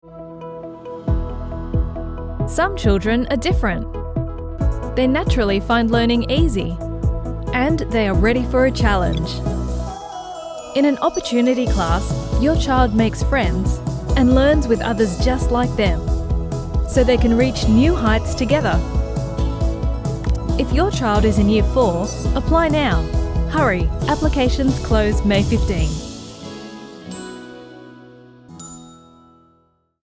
Female
English (Australian), English (Neutral - Mid Trans Atlantic)
Explainer Videos